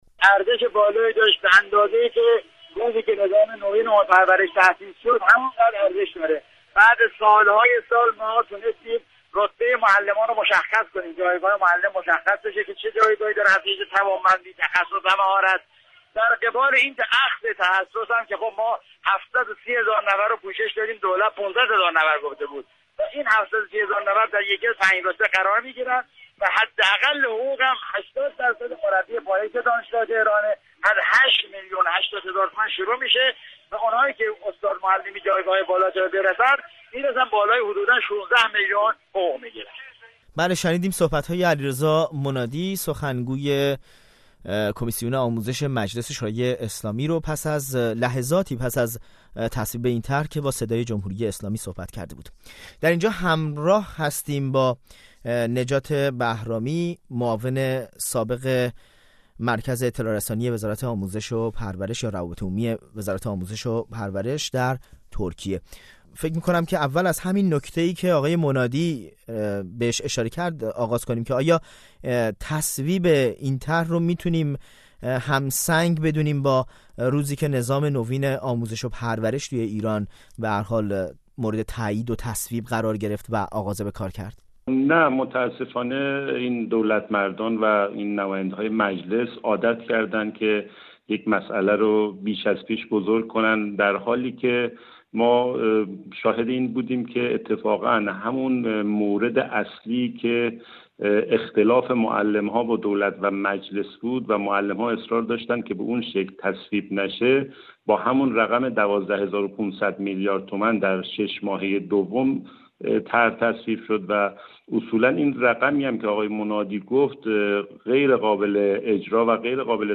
گفت‌وگو با یک مسئول سابق آموزش و پرورش درباره تصویب لایحه رتبه‌بندی